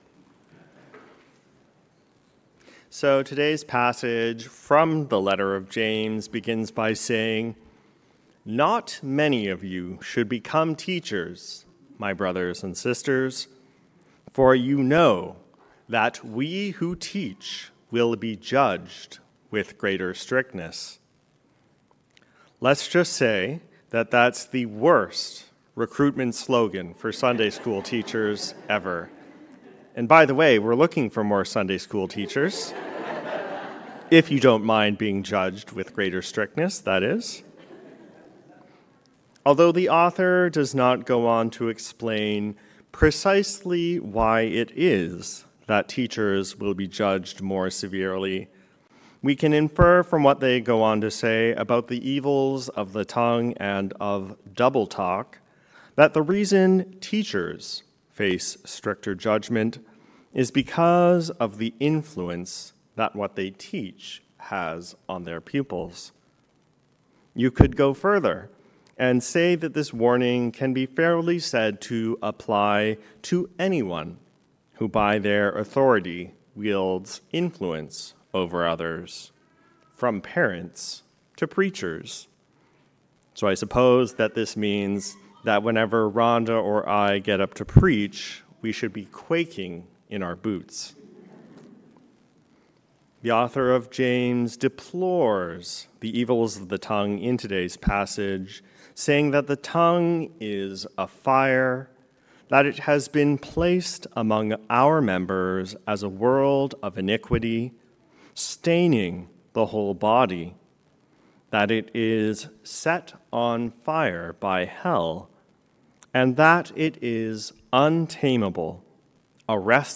The power of speech, for good or ill. A sermon on James 3:1-12